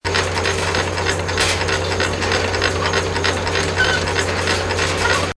CHQ_FACT_conveyor_belt.ogg